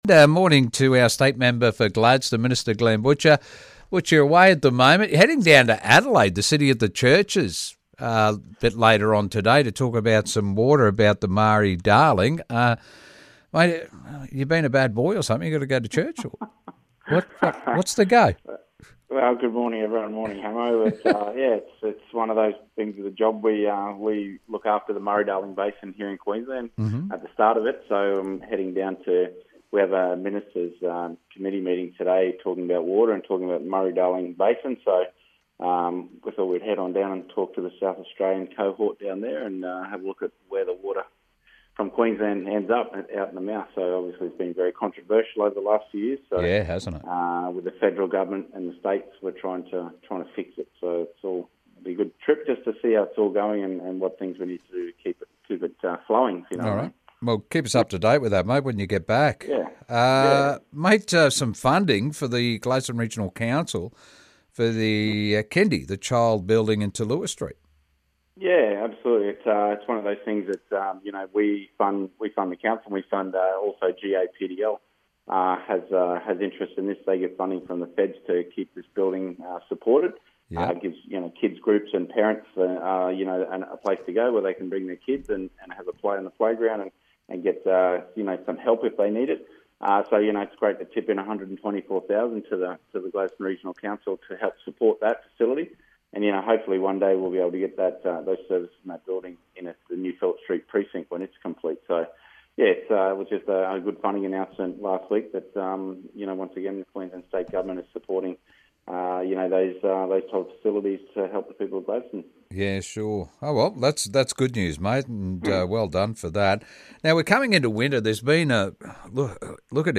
Download the QLD Check In app before May 1st. How? Member for Gladstone Glenn Butcher explains